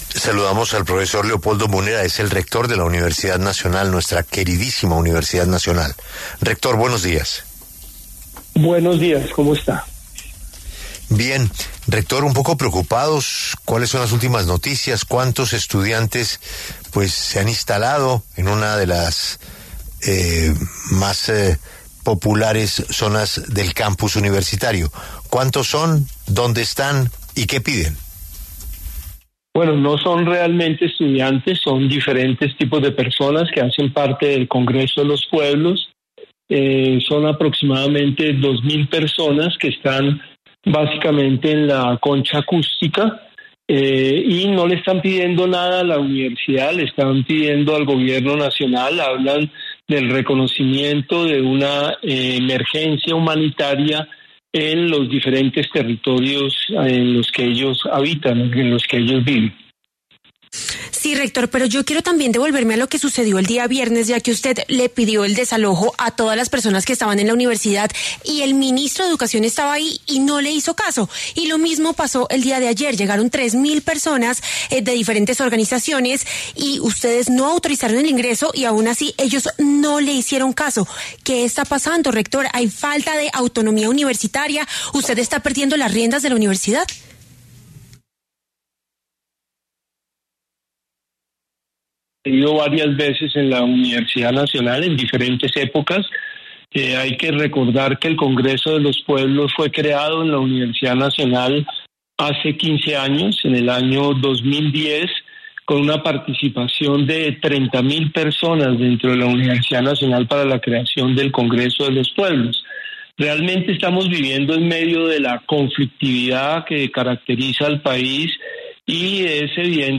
Ese día recibimos una amenaza contra la seguridad de la universidad y eso me parece muy importante tenerlo presente. Hay fuerzas que quieren desestabilizar a la Universidad Nacional y eso es preocupante”, señaló Múnera en entrevista con La W.